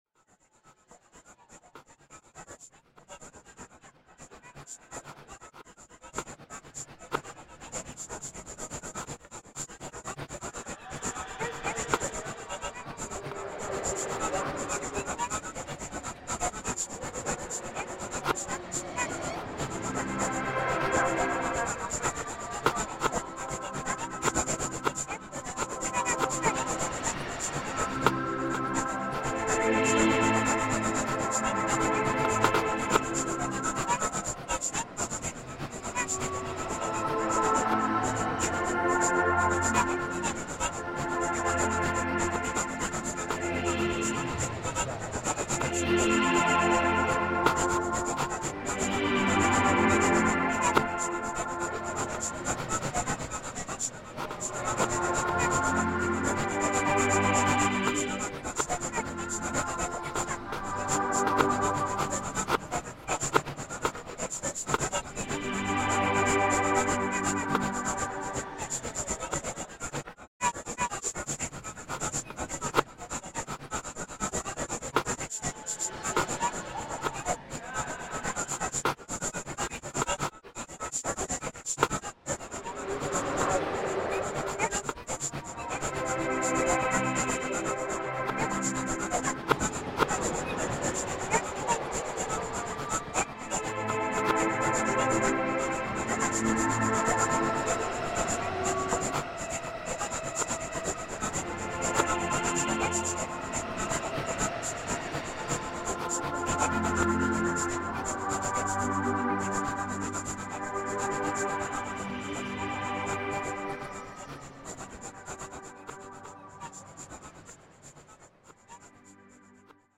New Jersey boardwalk reimagined